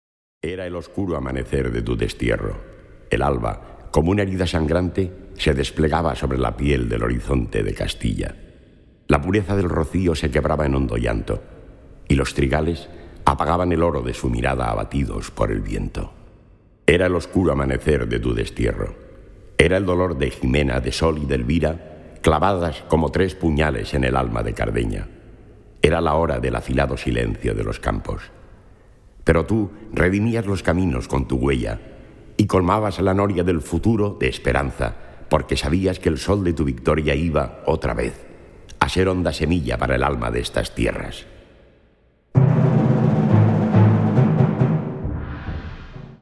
Categorie Harmonie/Fanfare/Brass-orkest
Bezetting Ha (harmonieorkest); SprS (verteller)